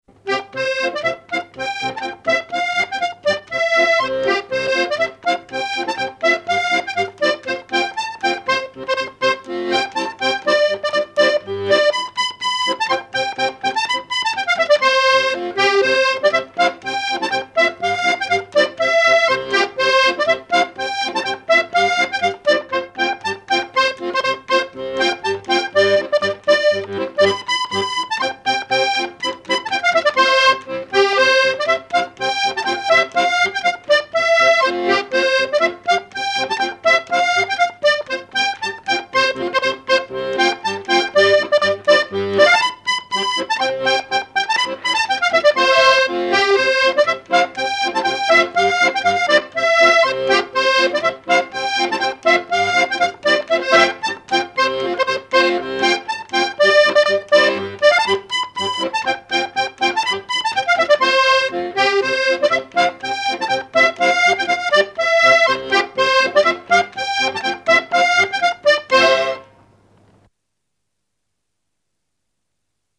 accordéon(s), accordéoniste ; musique traditionnelle ;
danse : polka des bébés ou badoise ;
Pièce musicale inédite